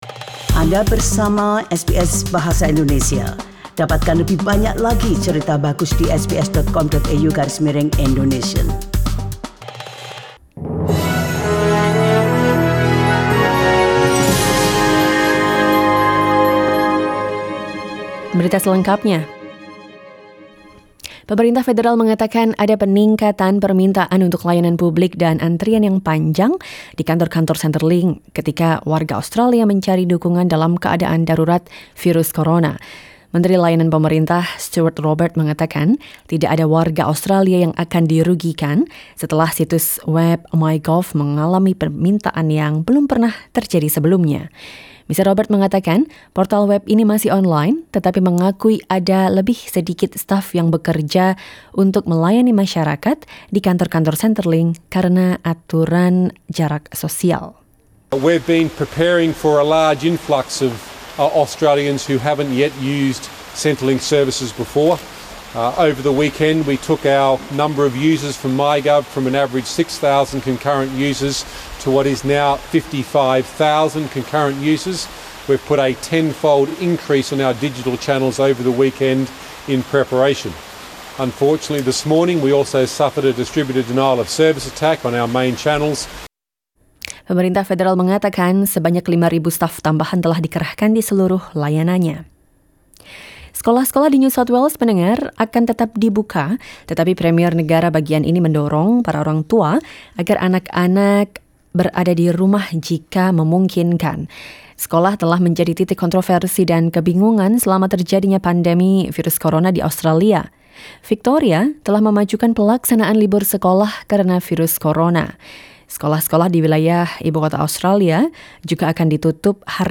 SBS Radio news in Indonesian - 23 March 2020
SBS Radio news in Indonesian, 23 March 2020 edition.